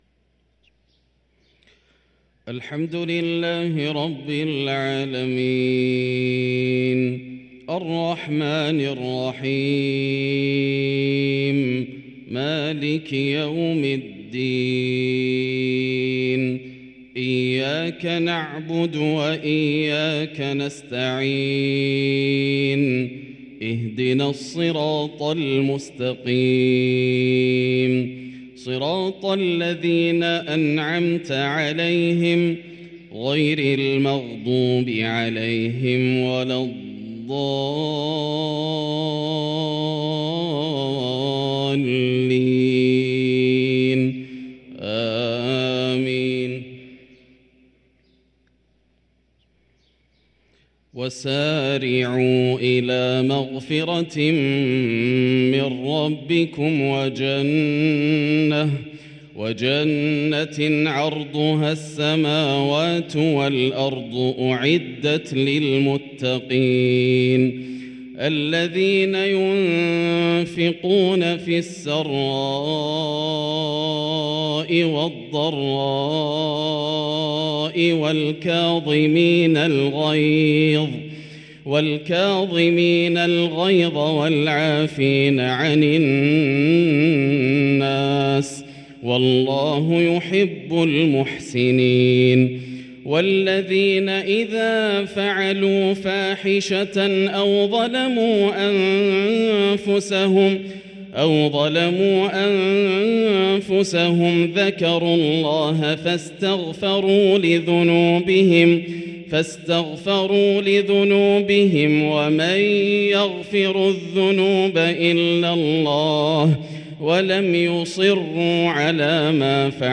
صلاة العشاء للقارئ ياسر الدوسري 7 شعبان 1444 هـ
تِلَاوَات الْحَرَمَيْن .